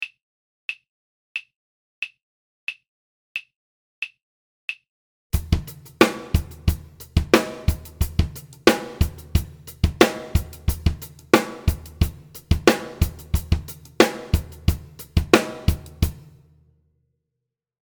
Voicing: Drum Set